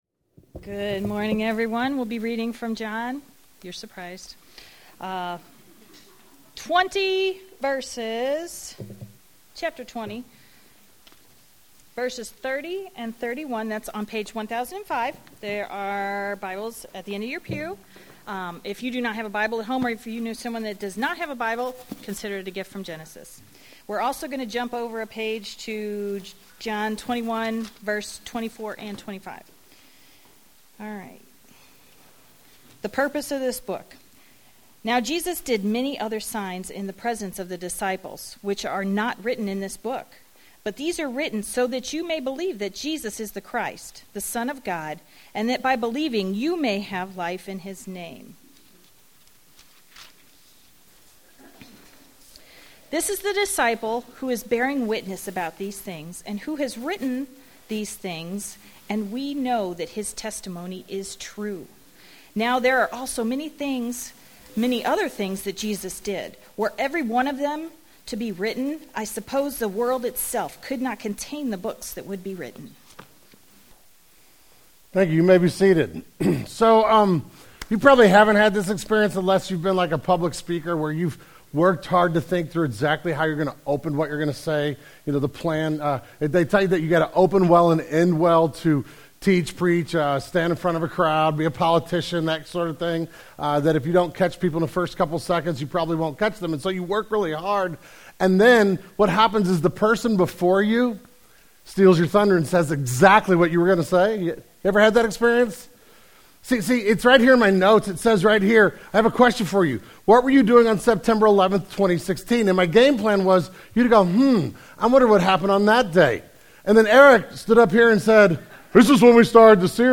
It has been well over 60 sermons, and almost a year and a half since we started the Believe series.